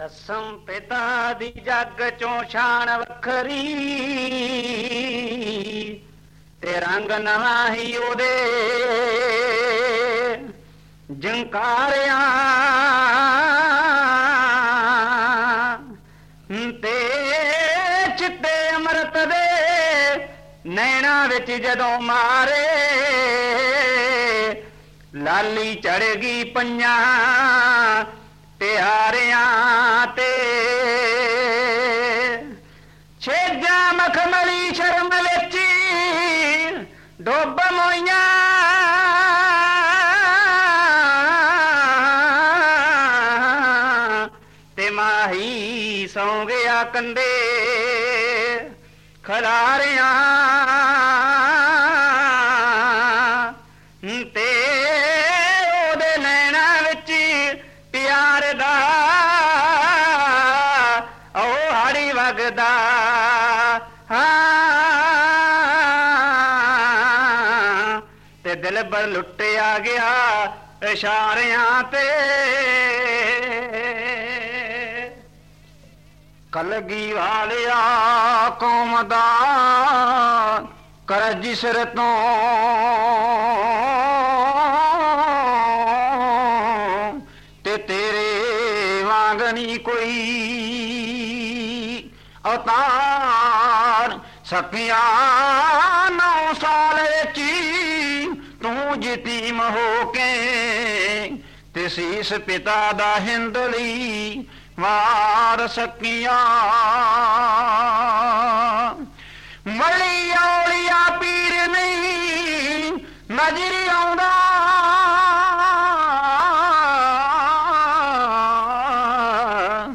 Genre: Kavishr